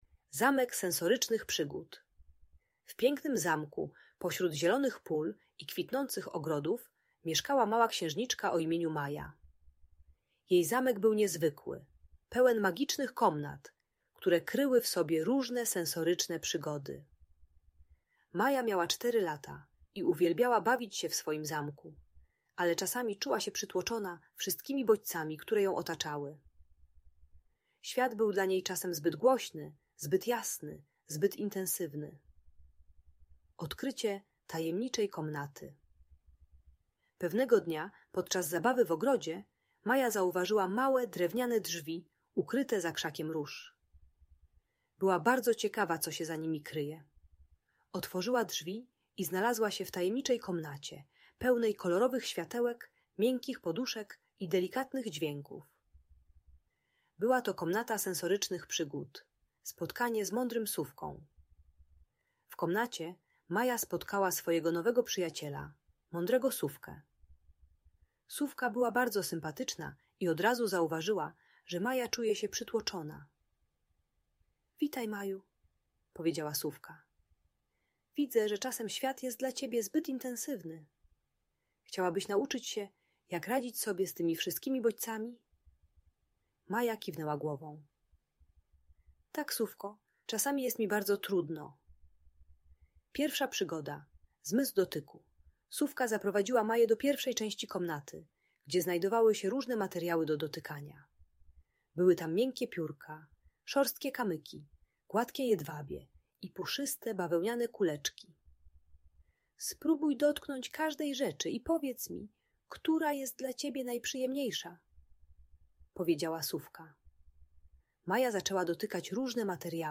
Zamek Sensorycznych Przygód - Audiobajka dla dzieci